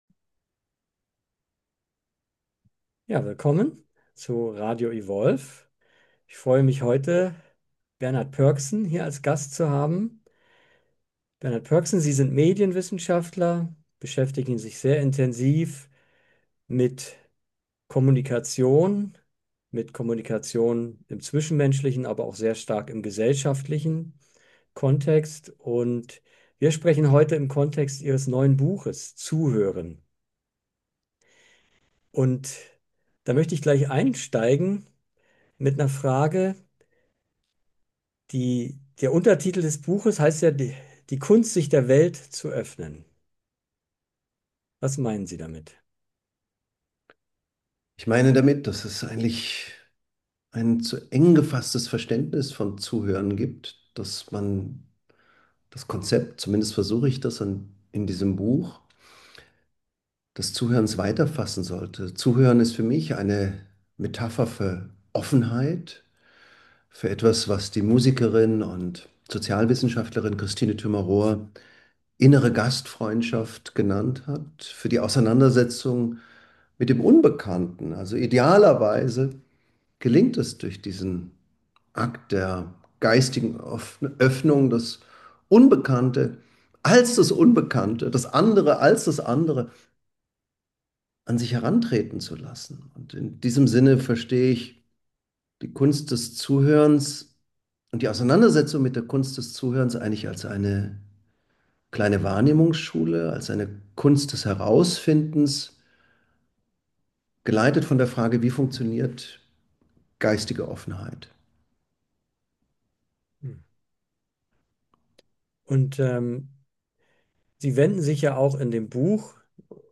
im Dialog mit Bernhard Pörksen